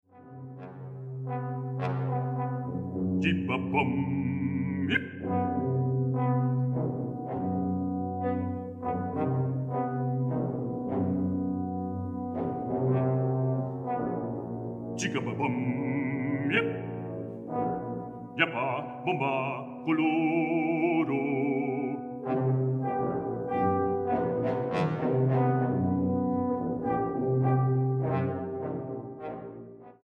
ópera infantil